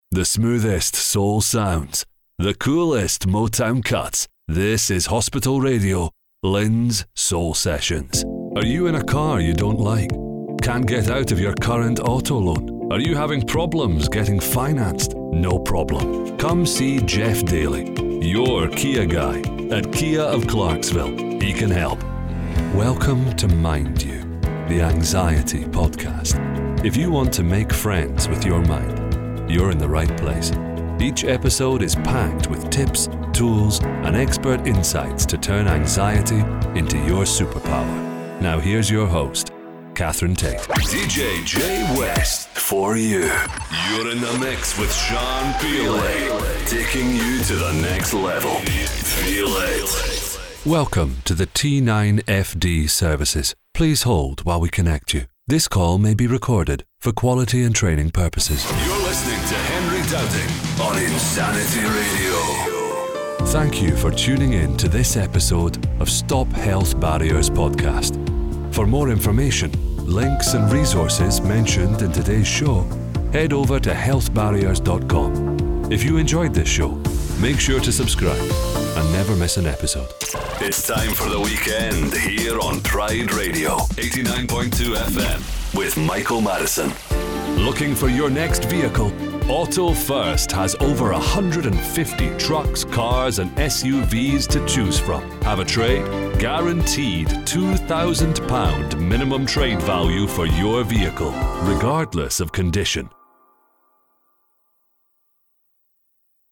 Radio and Podcast Jingle Showreel
He is proficient in UK and American accents, and his deep, resonant and clear voice has featured in over a hundred audiobooks to date.
Male
Confident
Relaxed